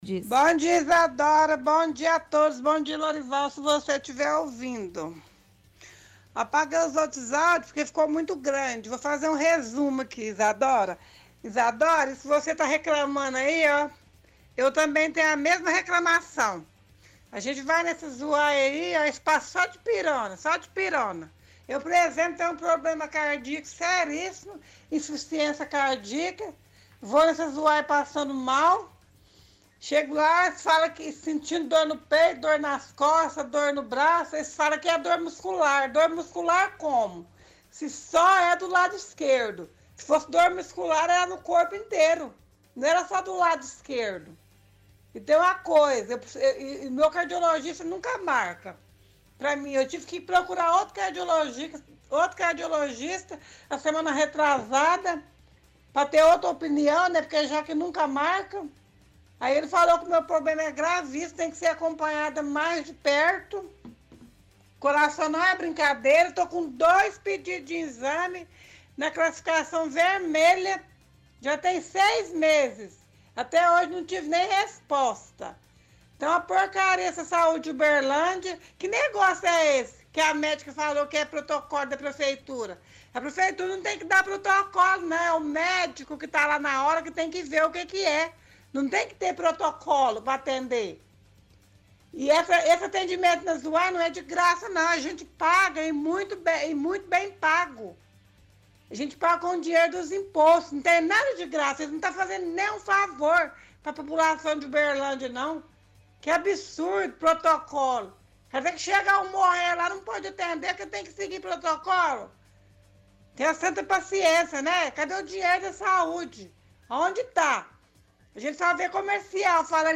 – Ouvinte reclama da UAI do bairro São Jorge.